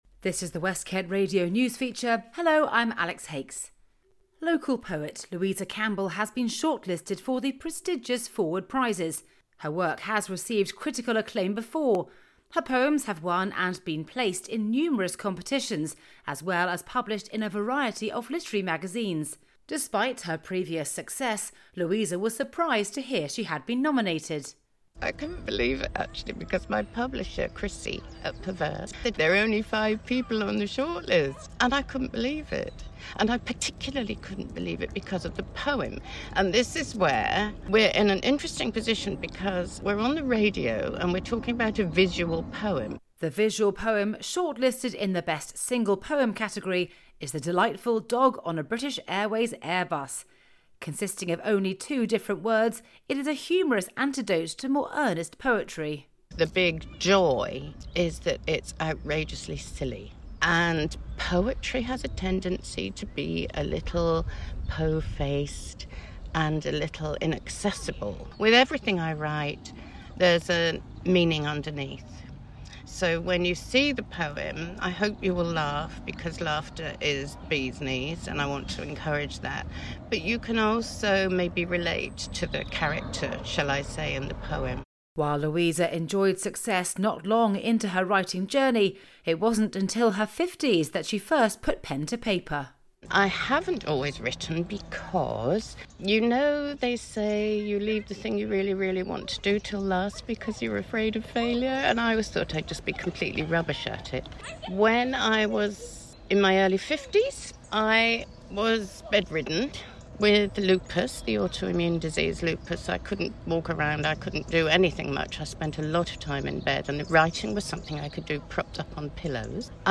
News Feature Audio below: